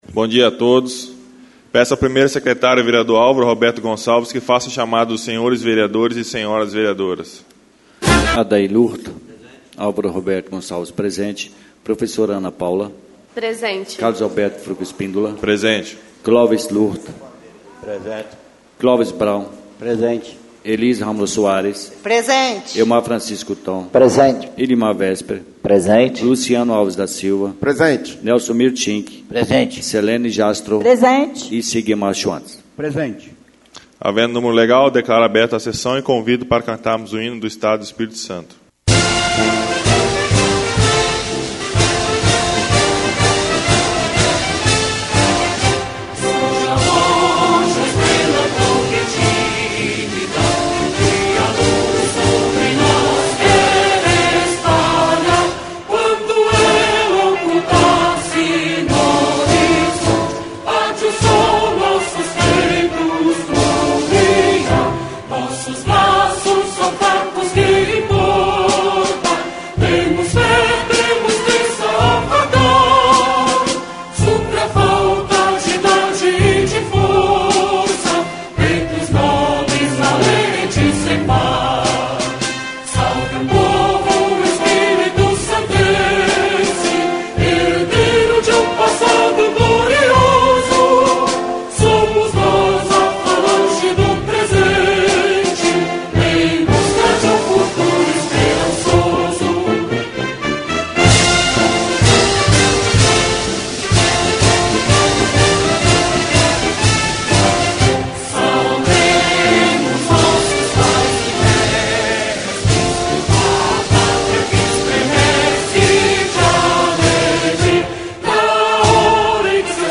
SESSÃO ORDINÁRIA Nº 12/2025